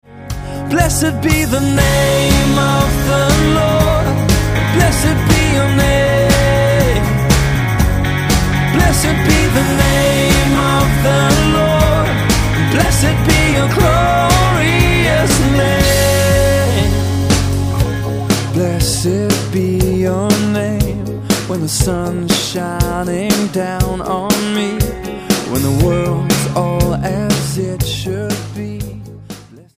STYLE: Rock